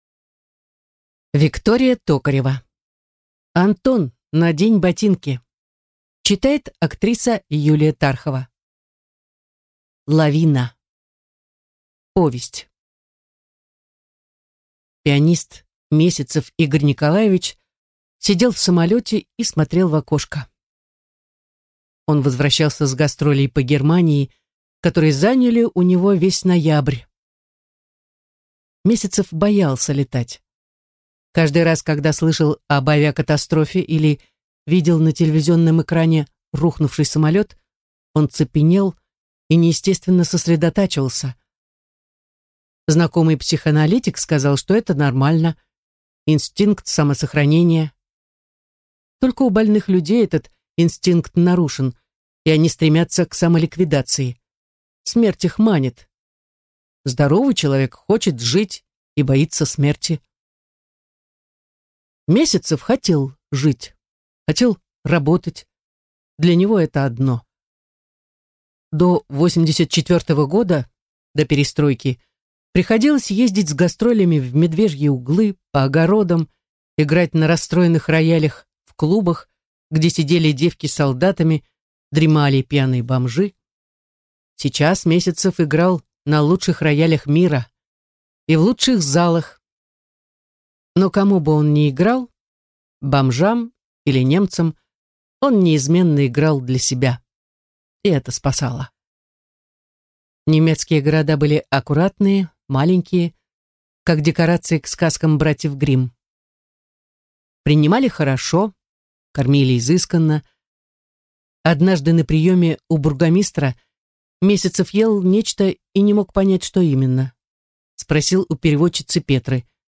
Аудиокнига Антон, надень ботинки!